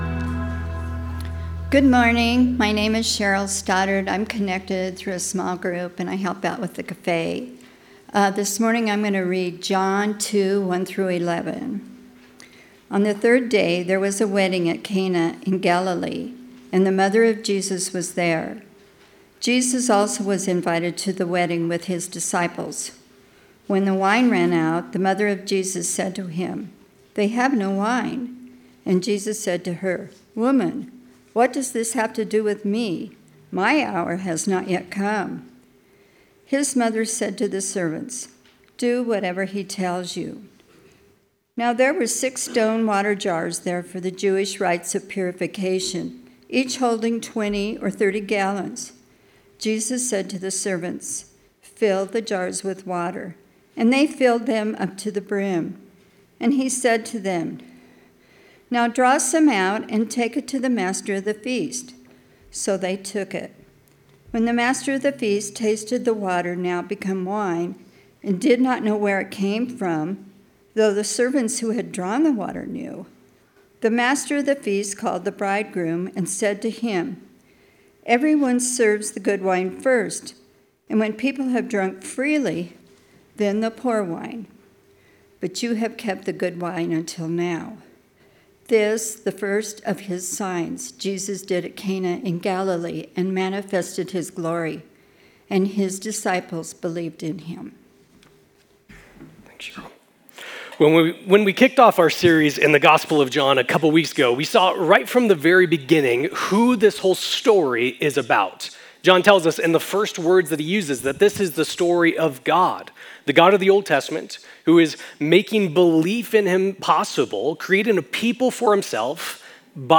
In this enlightening sermon